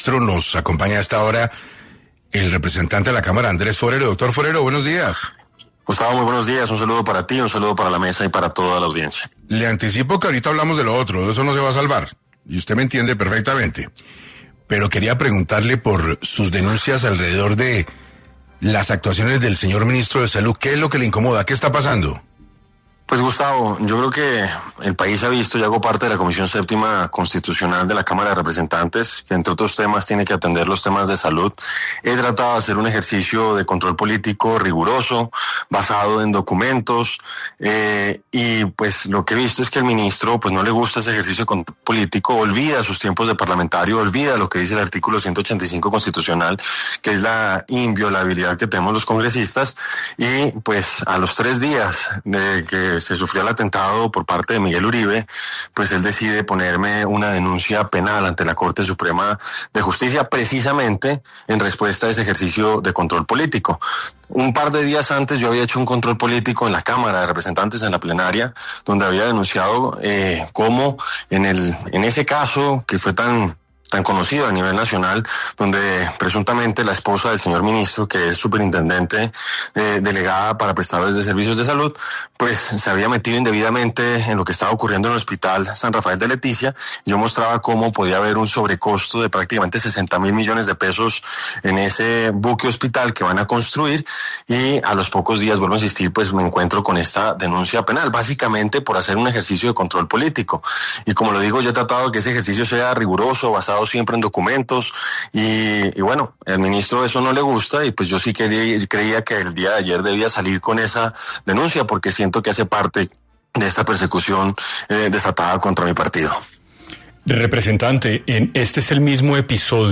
En entrevista con 6AM de Caracol Radio, Forero detalló como una denuncia penal interpuesta por Jaramillo, días después del atentado contra el fallecido senador Miguel Uribe Turbay, busca silenciar su labor de control político.